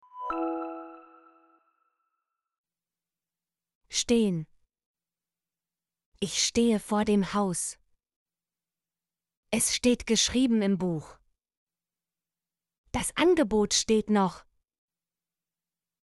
stehen - Example Sentences & Pronunciation, German Frequency List